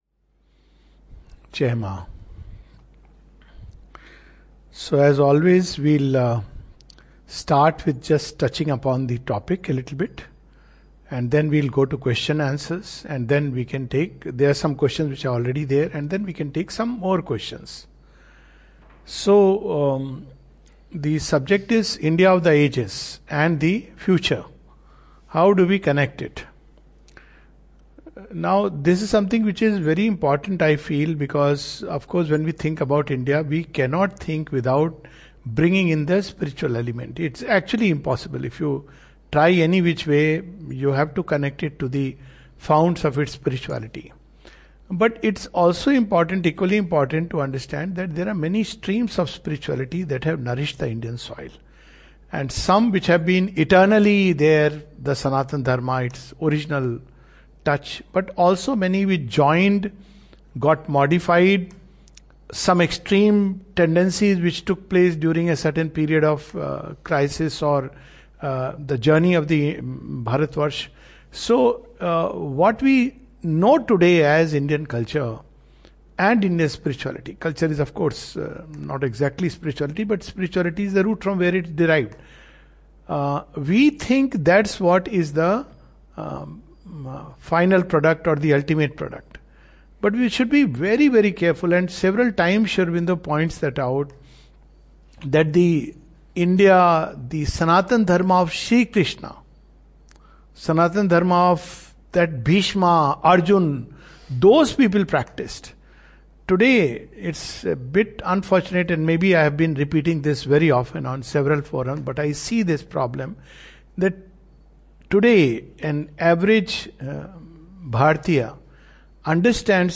This is a webinar which was followed by a few questions on the Caste census, a system of governance, Yogic Sadhana, Trigunatita, Integral Yoga in a nutshell.